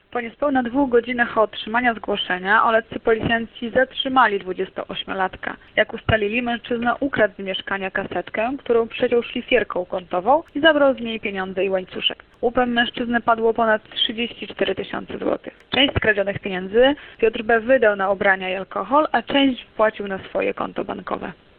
Informuje